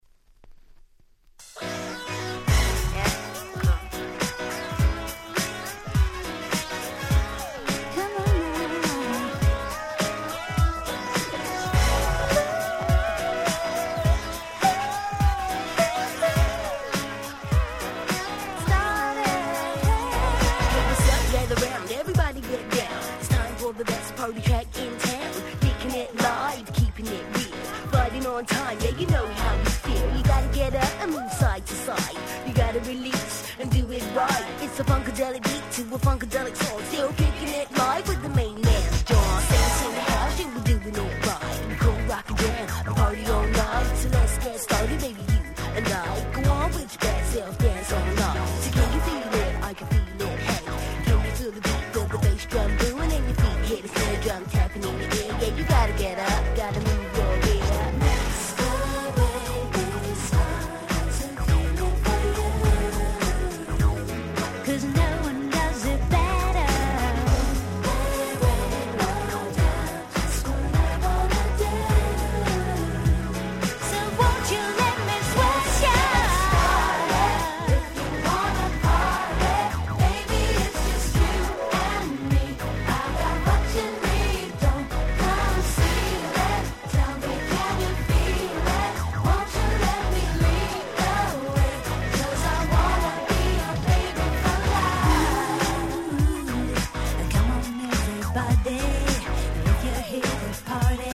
White Press Only Nice R&B Complilation !!!!!